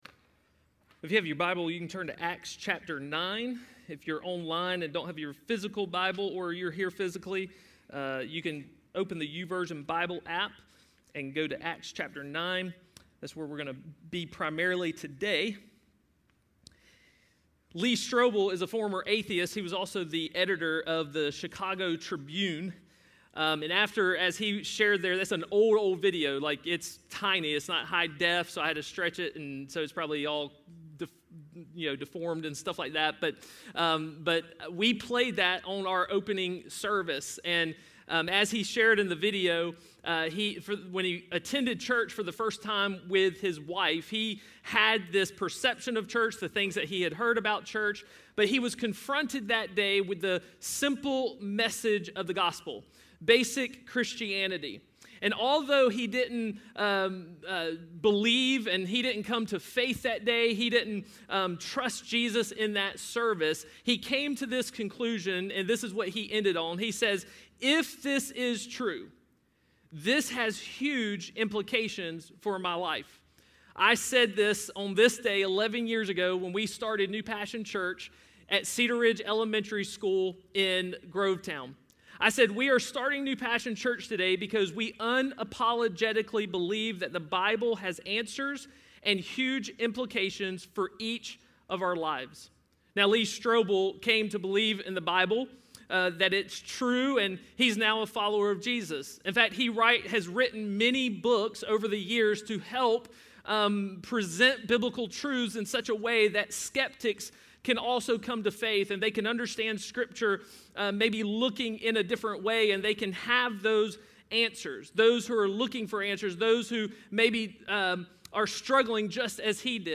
A message from the series "SOLO."